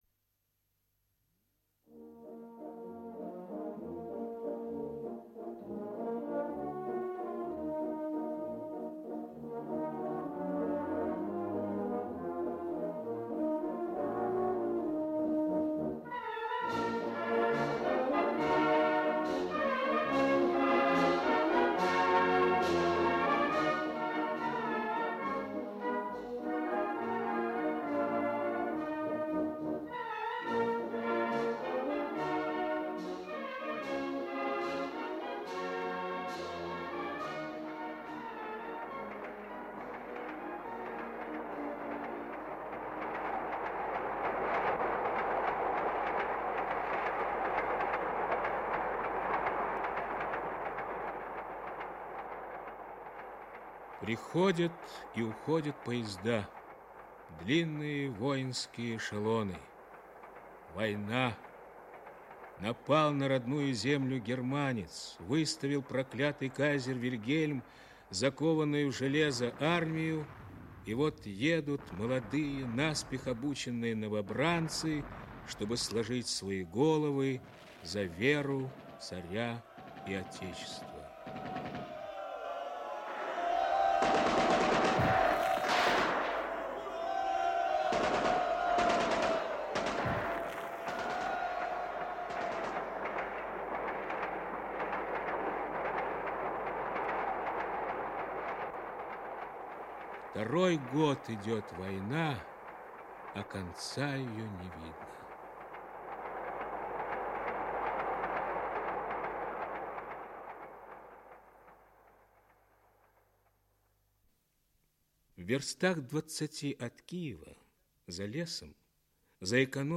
Аудиокнига Динка прощается с детством (спектакль) | Библиотека аудиокниг
Aудиокнига Динка прощается с детством (спектакль) Автор Валентина Осеева Читает аудиокнигу Олег Табаков.